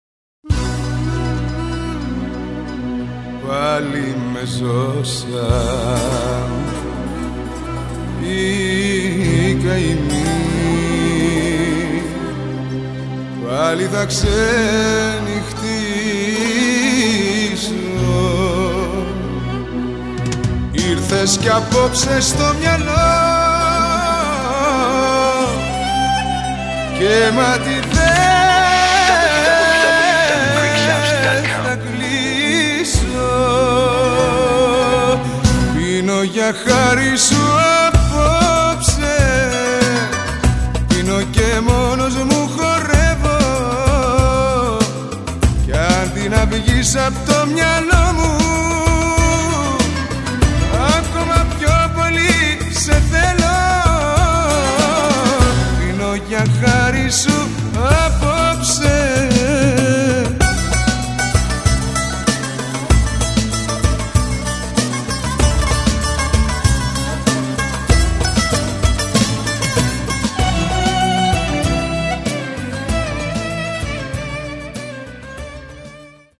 a 2CD set featuring today's modern Greek love songs.